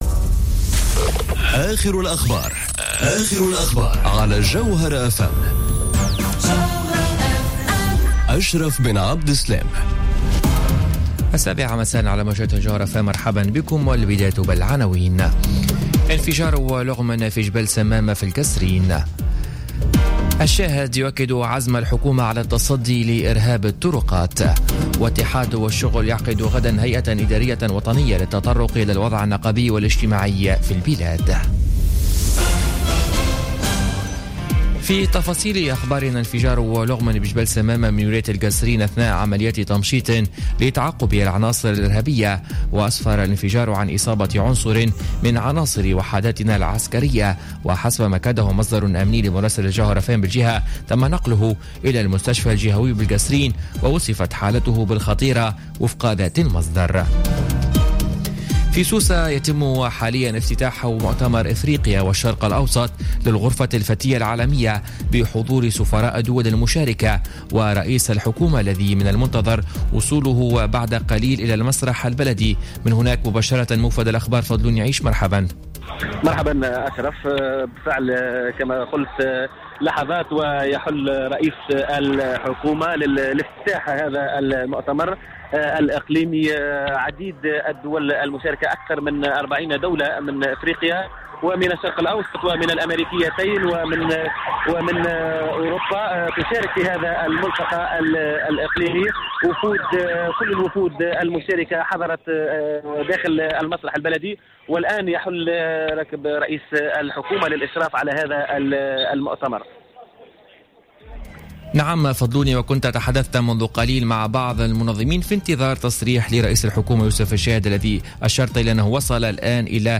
نشرة أخبار السابعة مساء ليوم الأربعاء 3 ماي 2017